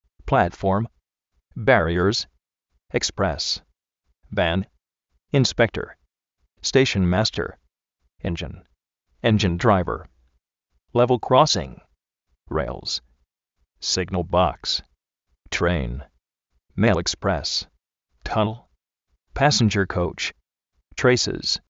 Vocabulario en ingles, diccionarios de ingles sonoros, con sonido, parlantes, curso de ingles gratis
plátform
bárriers
stéishn máster
ényin
lével krósin
tréin
pásenyer kóuch